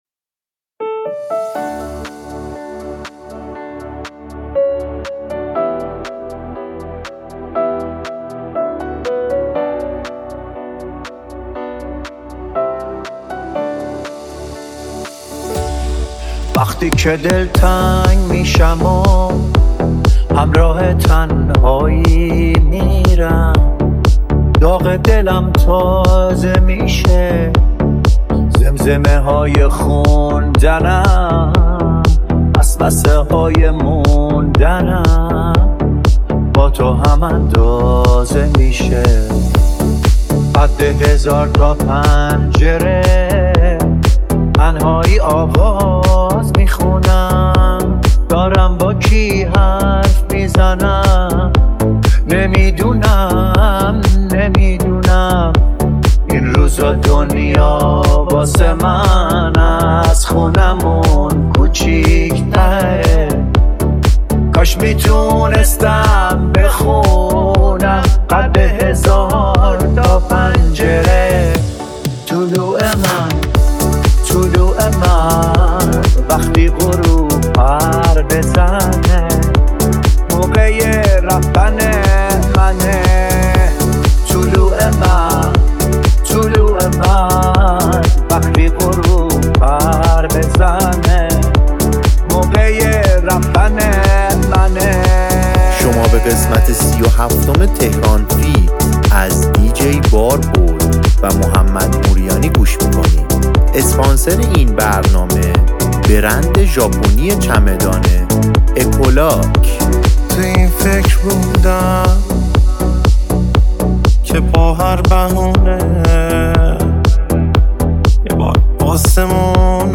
ریمیکس